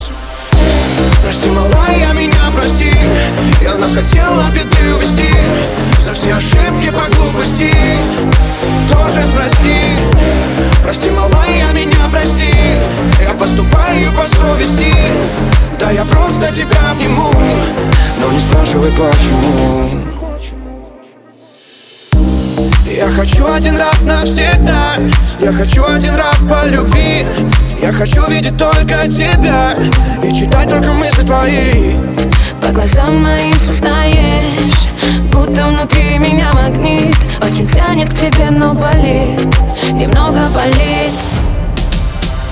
• Качество: 320, Stereo
ритмичные
громкие
Club House